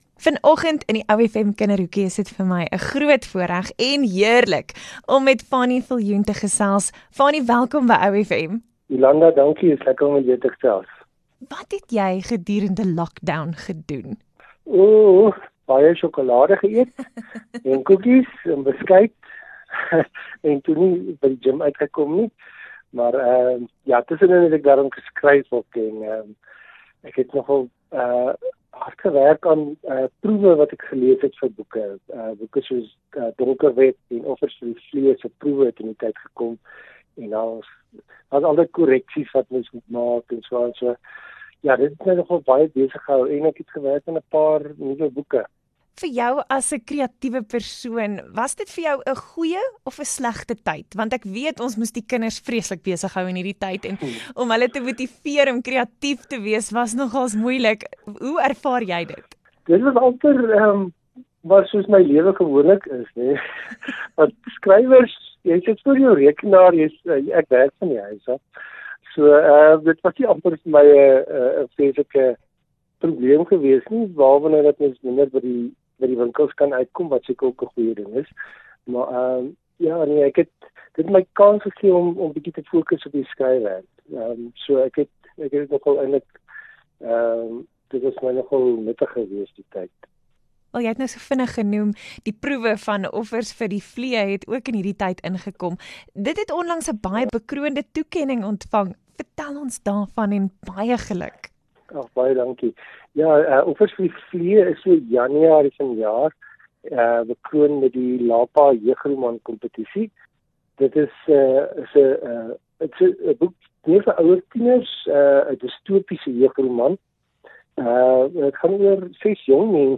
Hier is deel 1 van die gesprek.